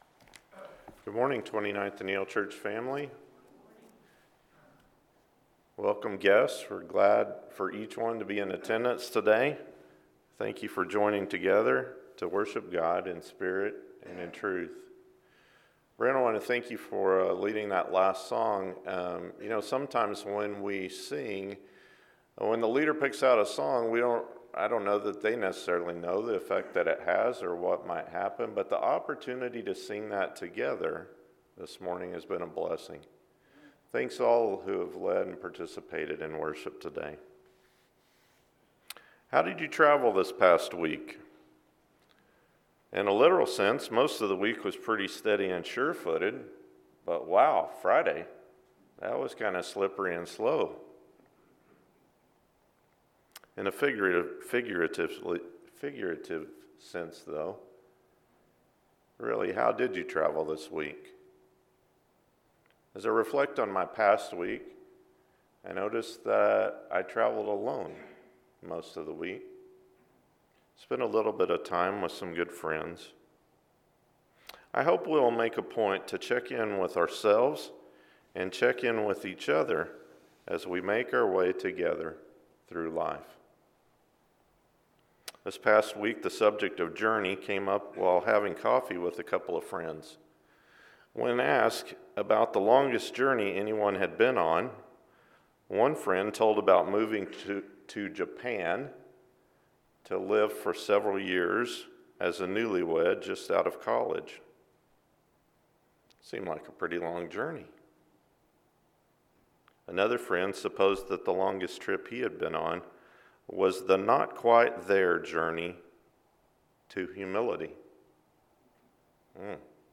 On the Road Together with Moses & the Israelites – Sermon